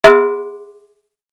B_BOUM.mp3